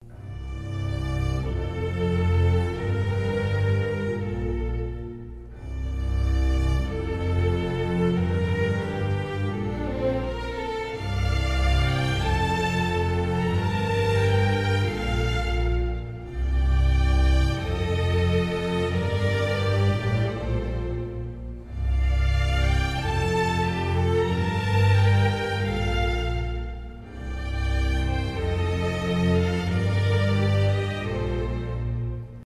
без слов , ost
инструментальные